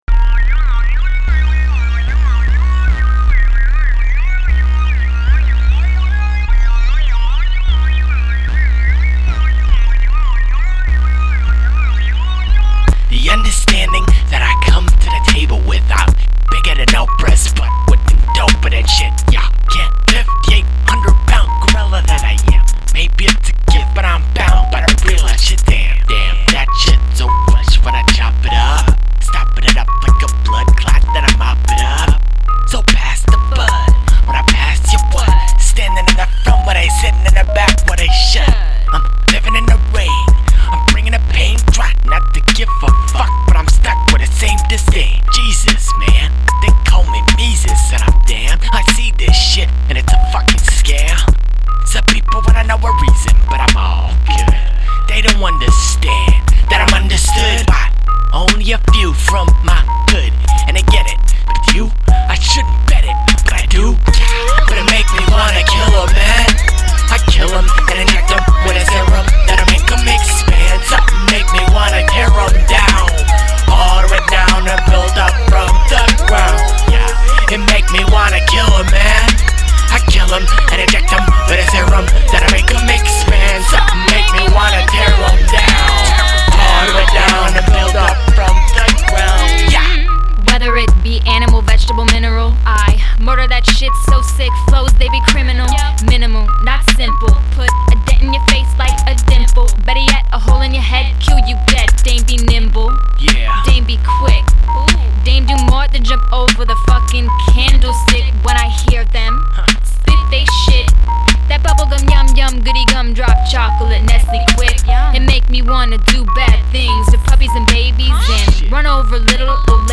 Recorded at Ground Zero Studios and Seattle Chop Shop